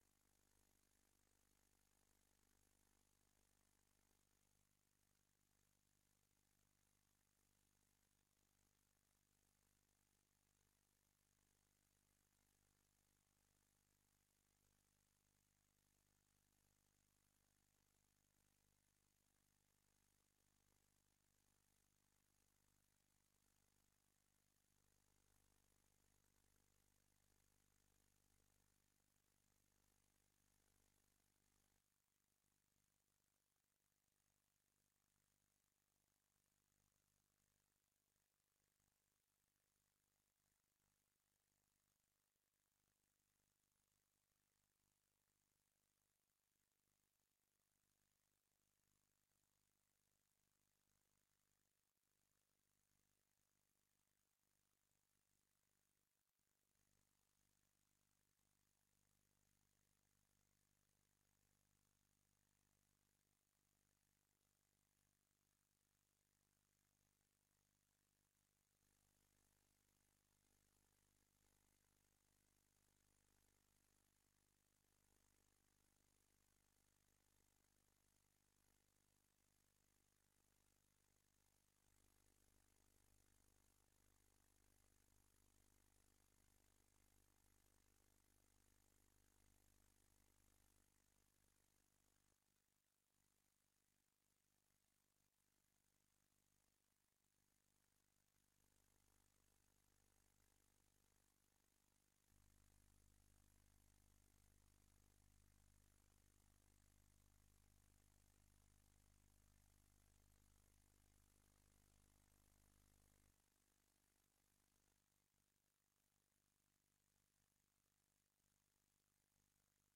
Werkbijeenkomst (openbaar) 14 oktober 2024 20:00:00, Gemeente Oude IJsselstreek
Locatie: DRU Industriepark - Conferentiezaal